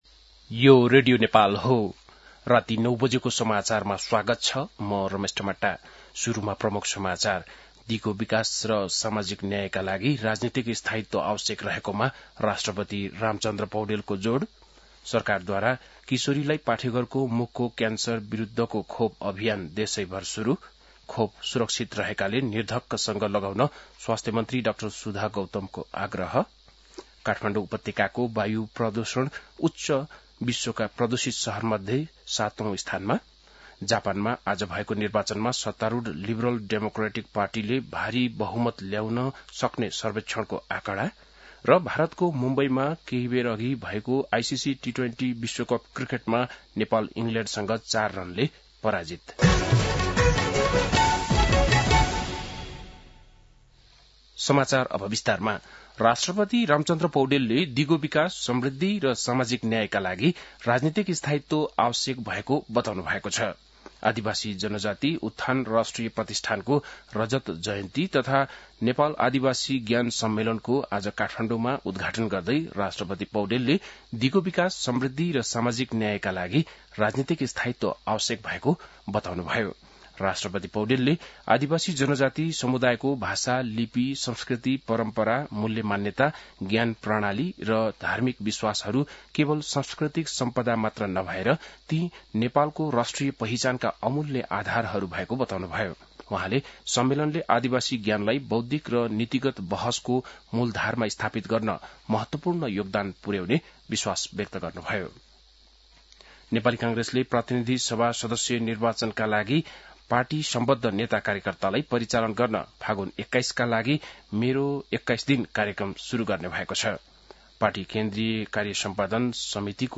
बेलुकी ९ बजेको नेपाली समाचार : २५ माघ , २०८२
9-pm-nepali-news-1-3.mp3